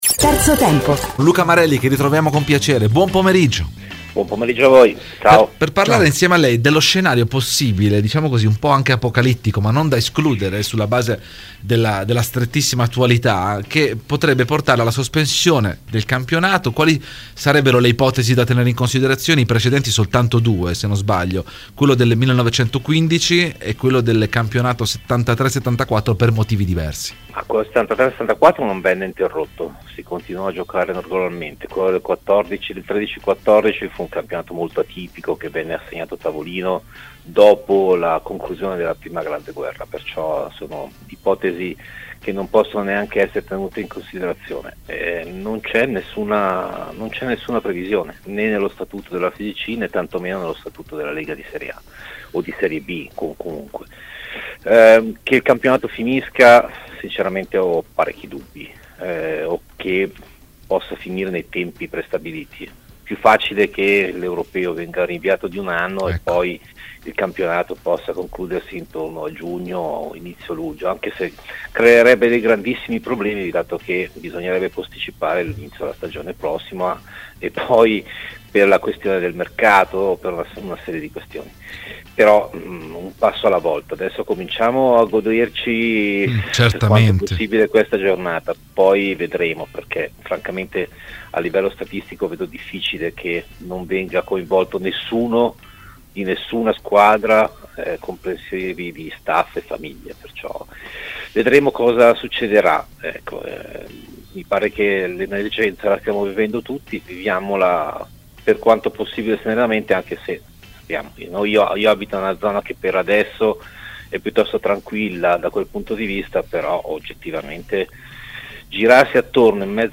Intervistato da Radio Bianconera nel corso di ‘Terzo Tempo’